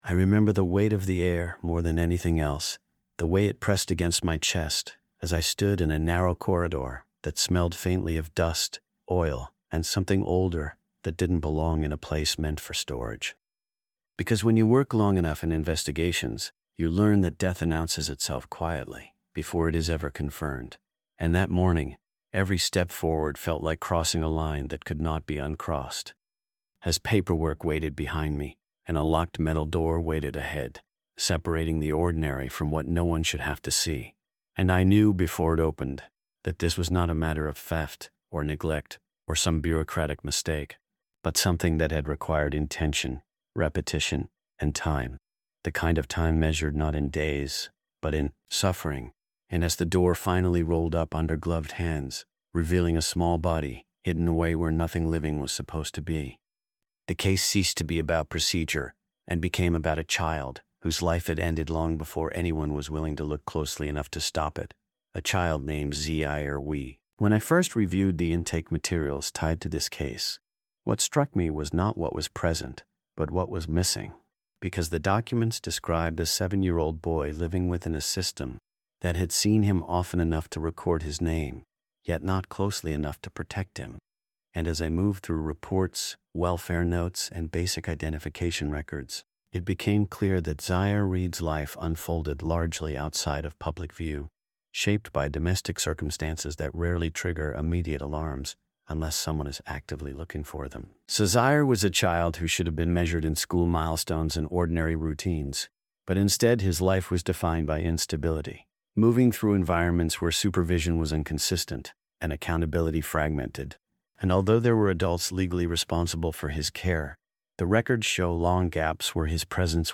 true-crime TTS audiobook
Told in a controlled first-person investigative voice